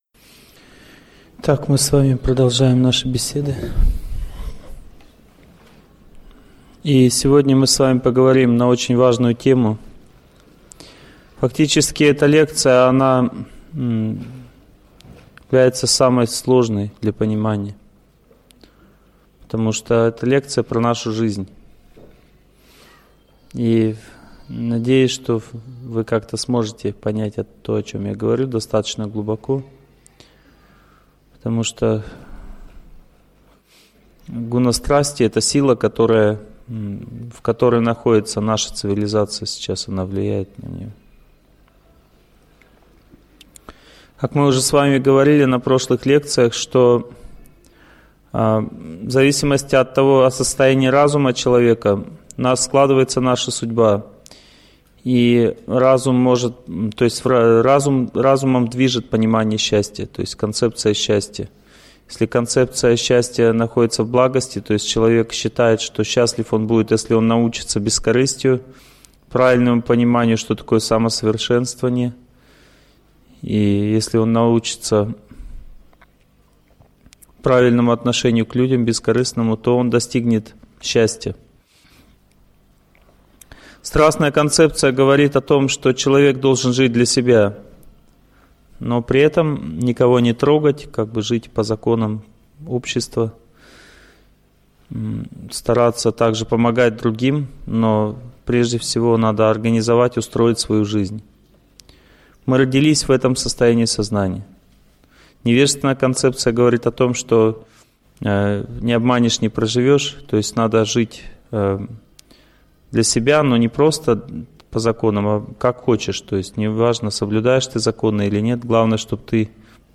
Аудиокнига Карма в страсти и невежестве | Библиотека аудиокниг